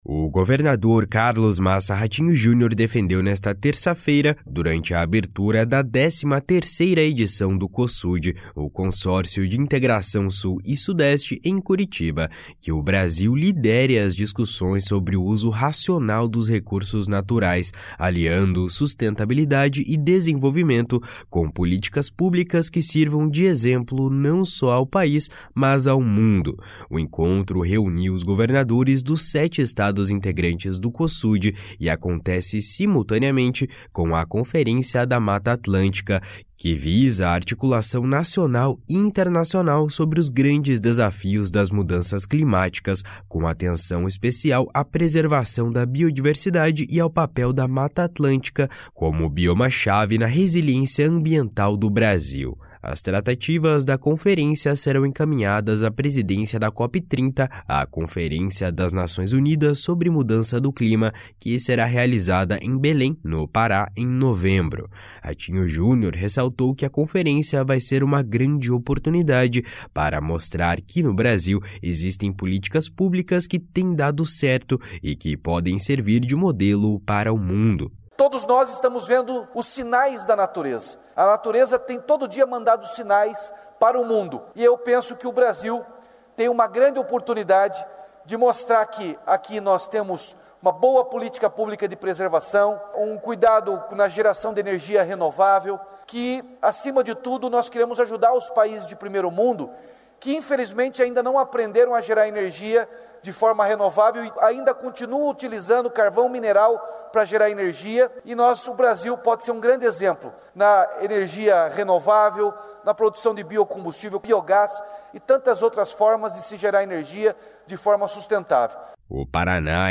O governador Carlos Massa Ratinho Junior defendeu nesta terça-feira, durante a abertura da 13ª edição do Cosud, Consórcio de Integração Sul e Sudeste, em Curitiba, que o Brasil lidere as discussões sobre o uso racional dos recursos naturais, aliando sustentabilidade e desenvolvimento com políticas públicas que sirvam de exemplo não só ao País, mas ao mundo.
// SONORA RATINHO JUNIOR //